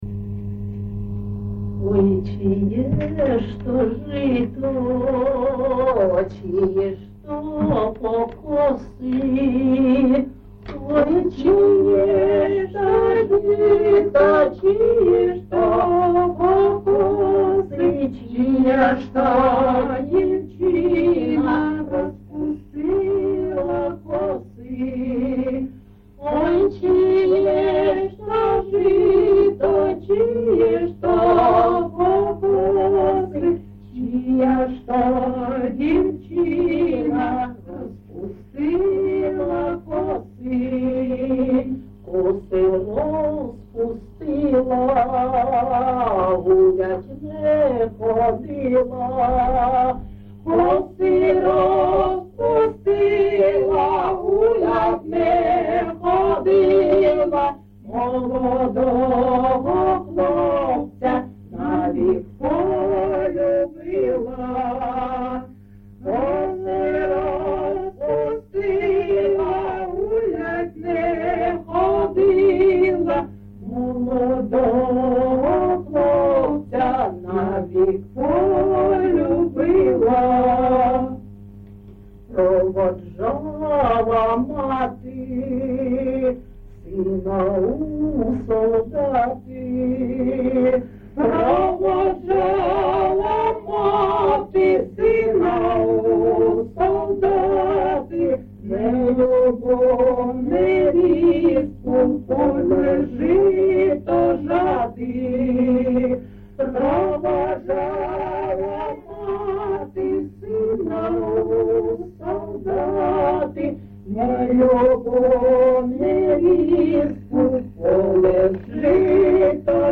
ЖанрПісні з особистого та родинного життя, Балади
Місце записум. Костянтинівка, Краматорський район, Донецька обл., Україна, Слобожанщина